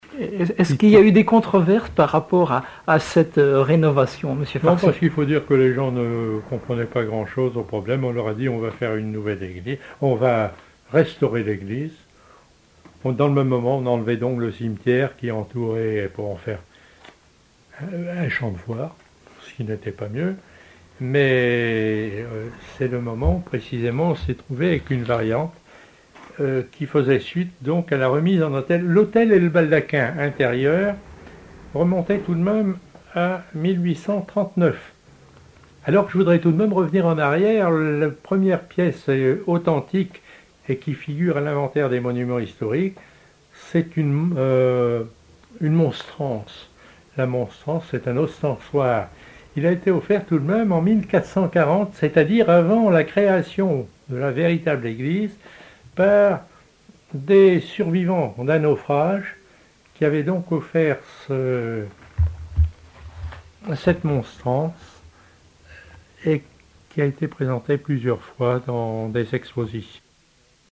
Enquête Emissions de la radio RCF Vendée
Catégorie Témoignage